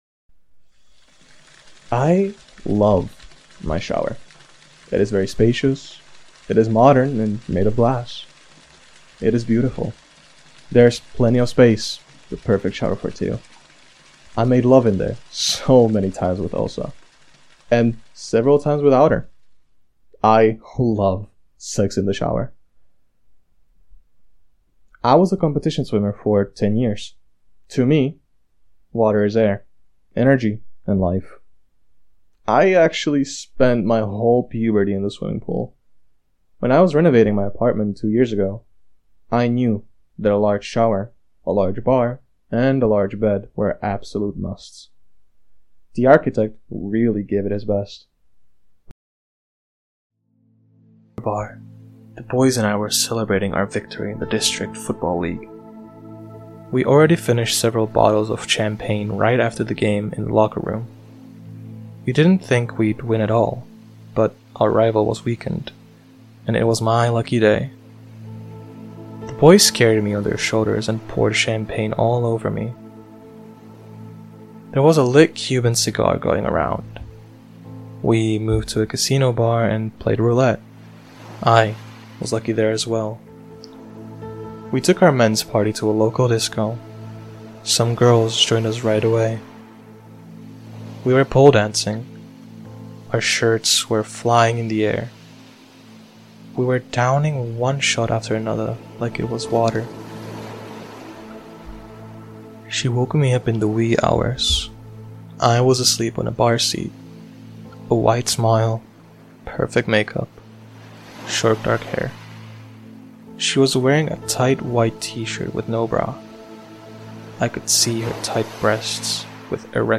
Audio knihaLife is Awesome!
Ukázka z knihy